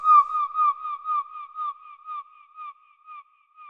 Category 🌿 Nature
ambient animal bird birdsong delay dub echo effect sound effect free sound royalty free Nature